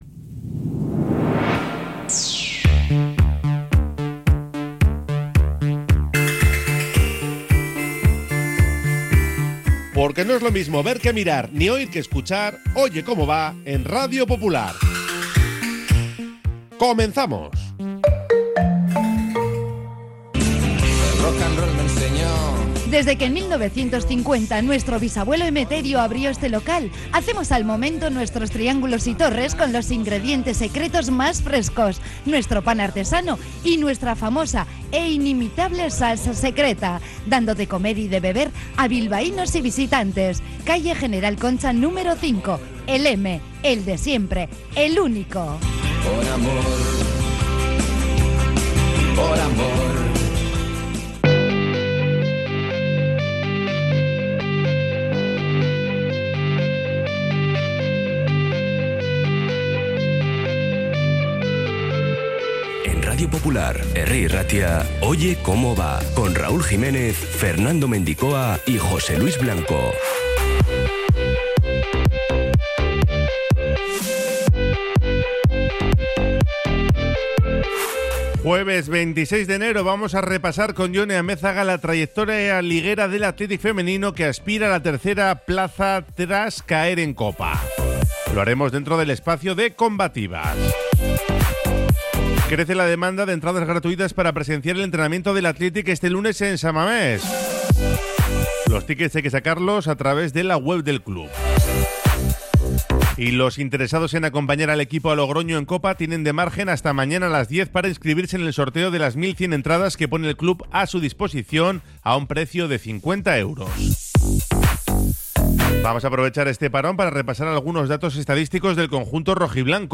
Oye Cómo Va 26-12-24 | Entrevista